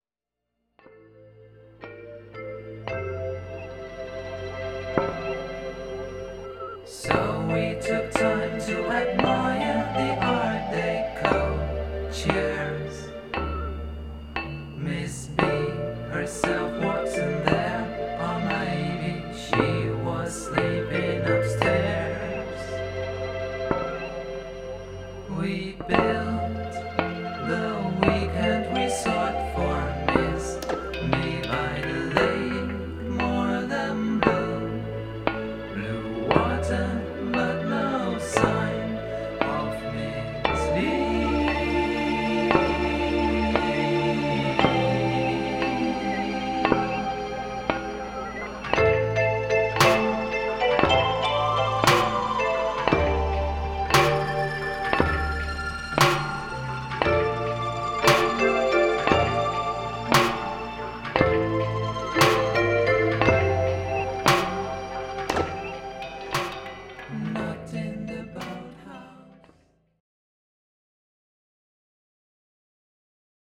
The close harmony singing is still there, as is the mature